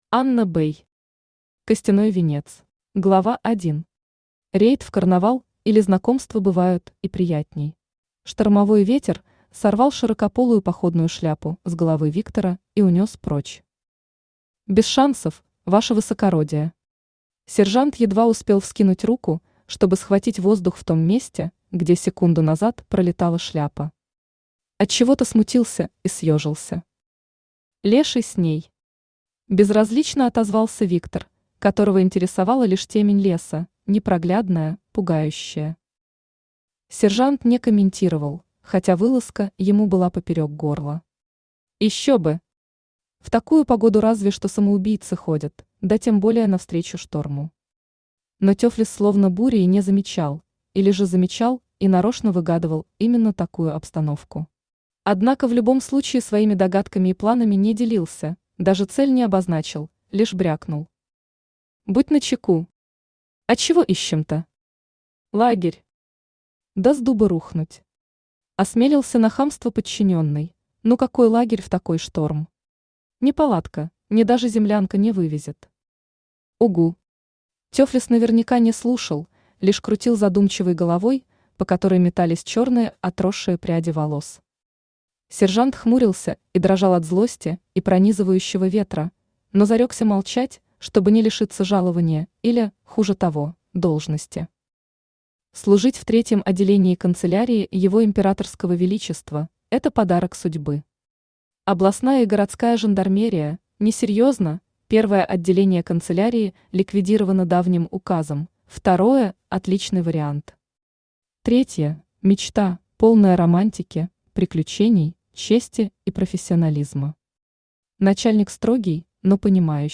Аудиокнига Костяной Венец | Библиотека аудиокниг